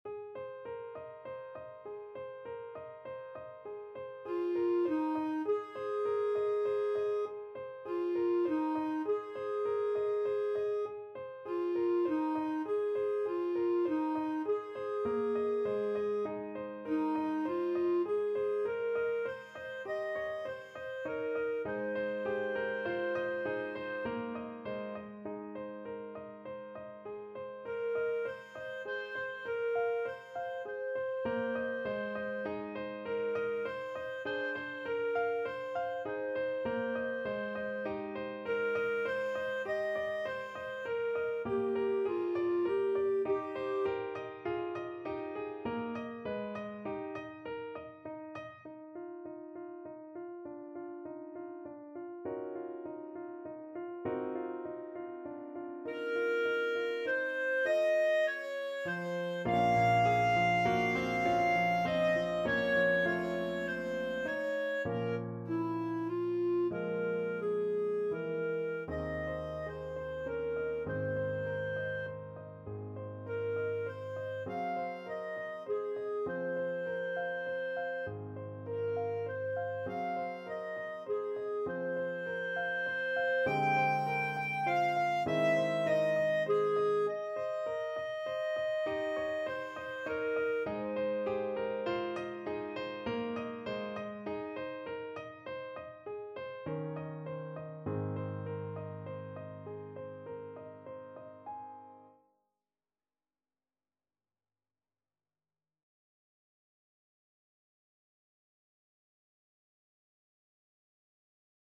Clarinet version
~ = 120 Allegretto
9/4 (View more 9/4 Music)
Classical (View more Classical Clarinet Music)